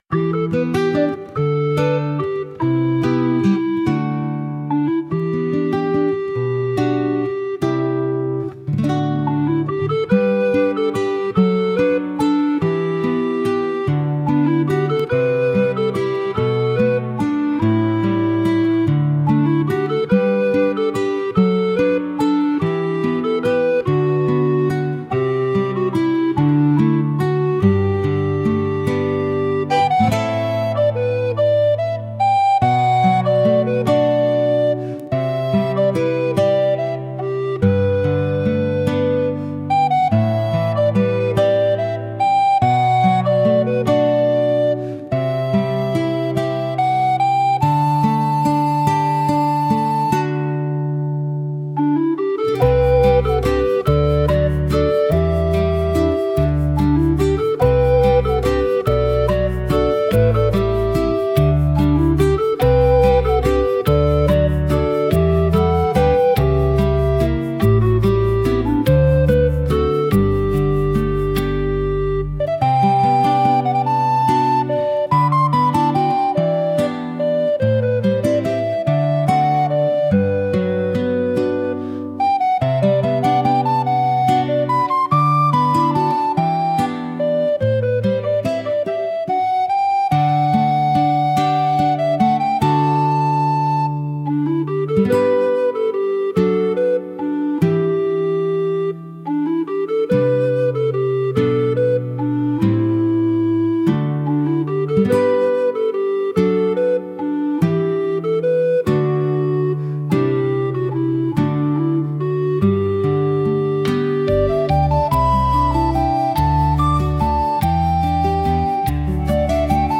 かわいい , ほのぼの , ギター , ムービー , リコーダー , 冬 , 日常 , 昼 , 穏やか , 静か